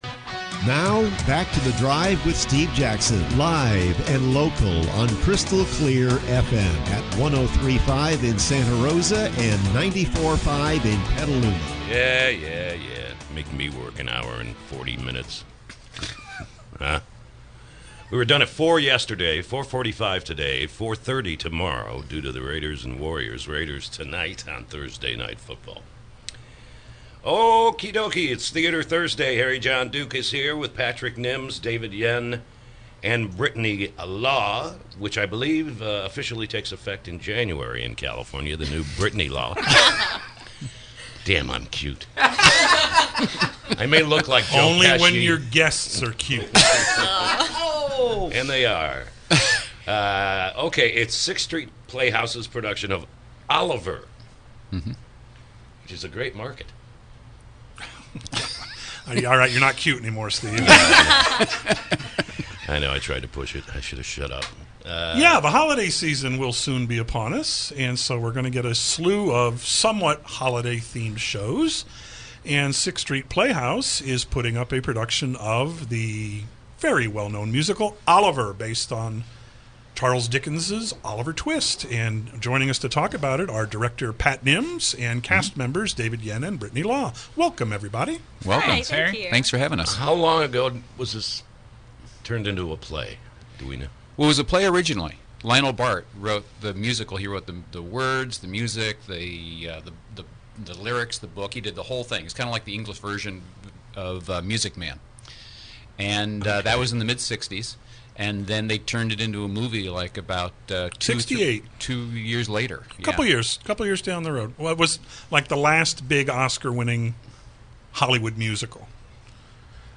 KSRO Interview: “Oliver!”